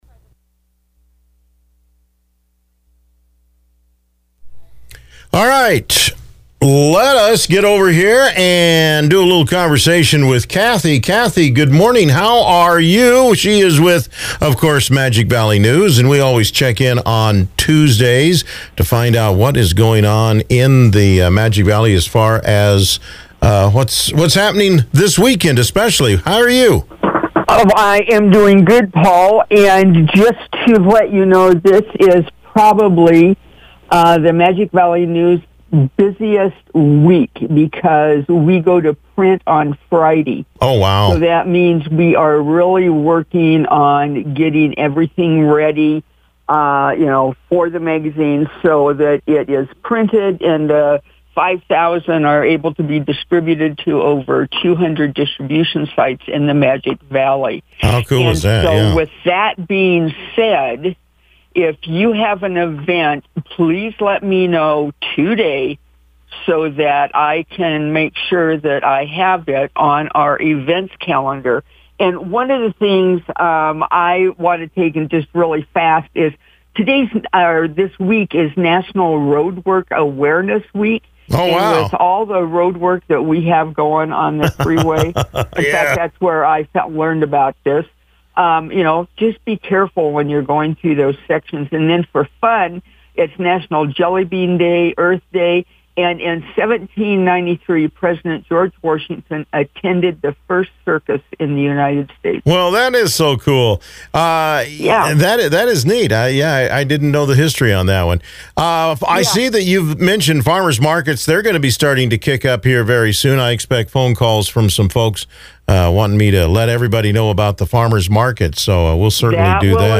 Radio Chats